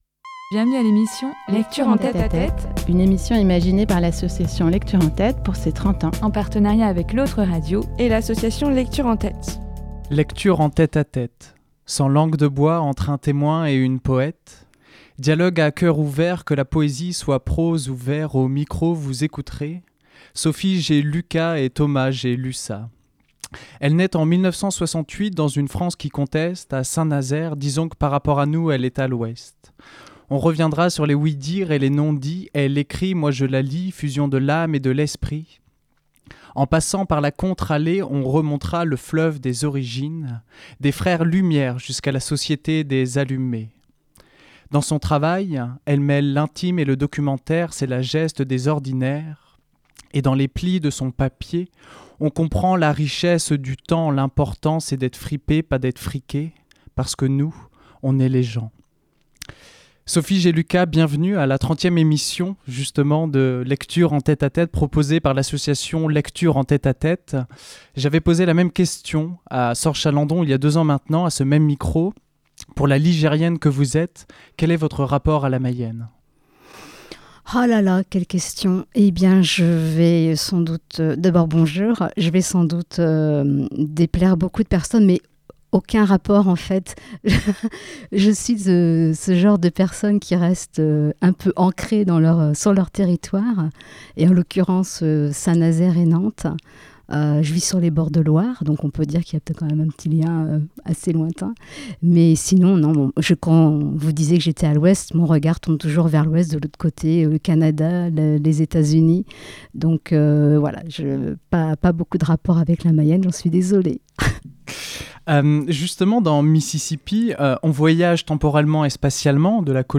Extrait lu